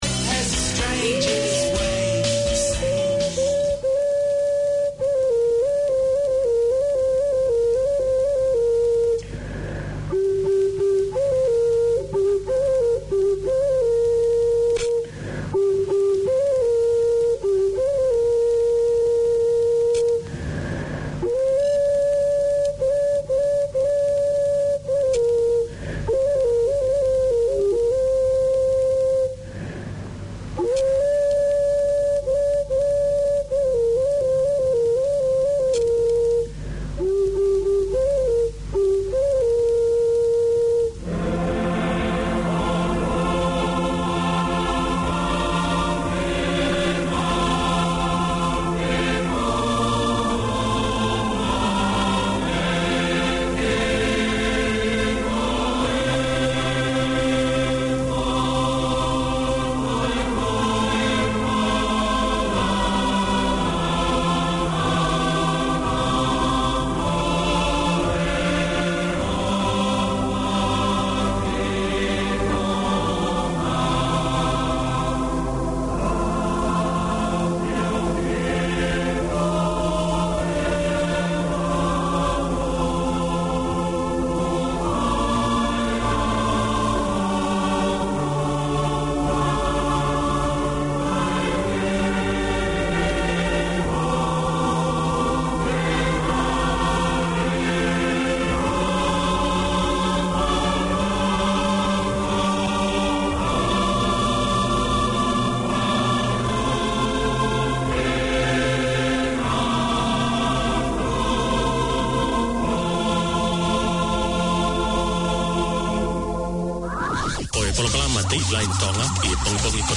Community Access Radio in your language - available for download five minutes after broadcast.